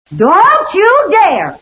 I Love Lucy TV Show Sound Bites